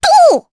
Rephy-Vox_Attack2_jp.wav